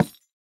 Minecraft Version Minecraft Version 1.21.5 Latest Release | Latest Snapshot 1.21.5 / assets / minecraft / sounds / block / cherry_wood_hanging_sign / break2.ogg Compare With Compare With Latest Release | Latest Snapshot